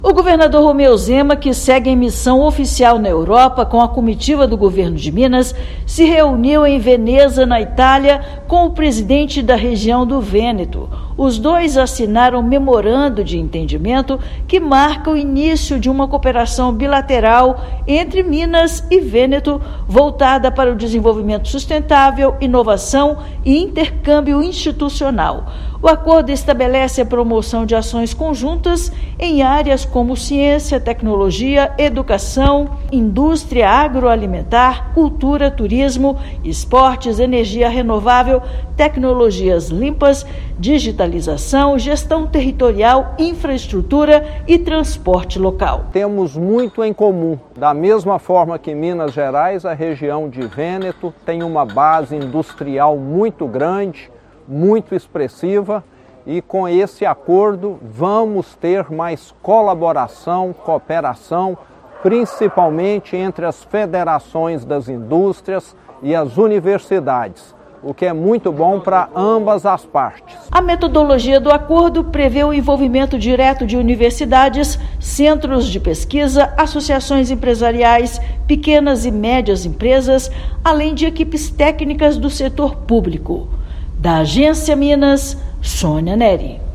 [RÁDIO] Minas Gerais firma parceria inédita com a região do Vêneto durante missão oficial na Itália
Assinatura de Memorando de Entendimento (MoU) consolida cooperação estruturada e de longo prazo. Ouça matéria de rádio.